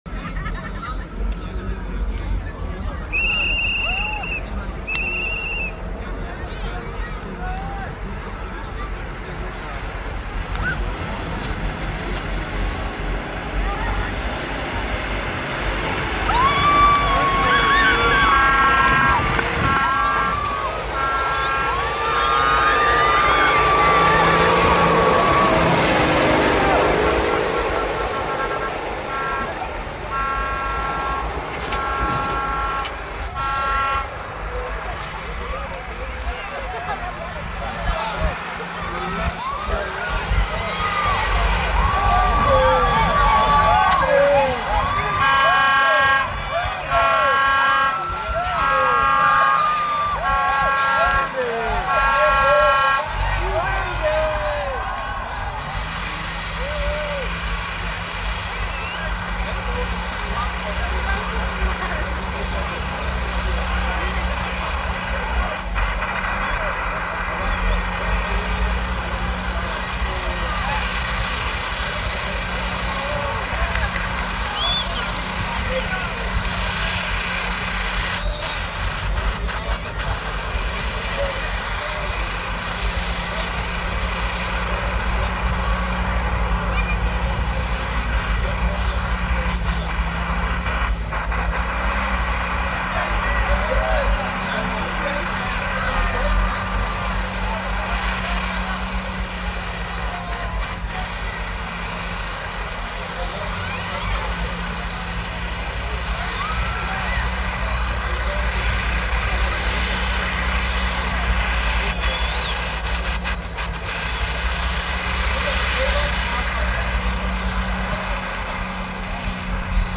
💪🚙💦 With the crowd cheering like it’s the finals of Mud Wrestling Championship!